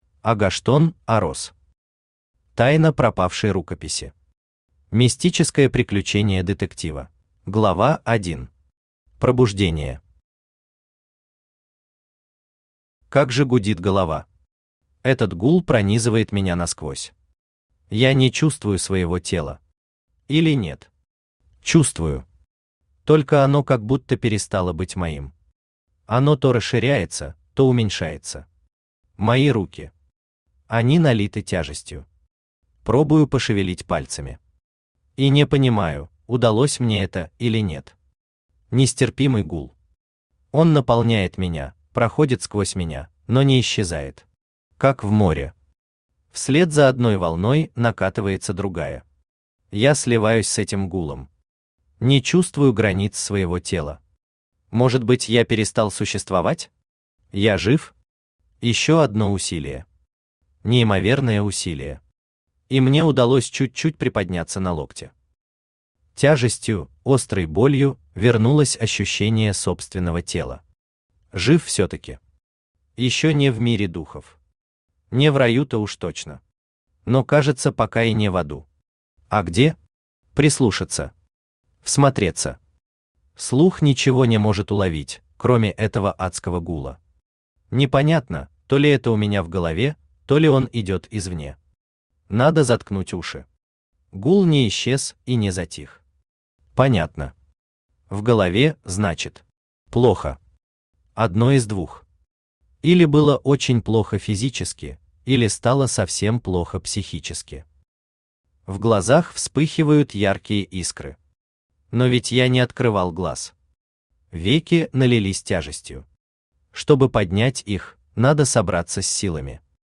Мистическое приключение детектива Автор Агоштон Орос Читает аудиокнигу Авточтец ЛитРес.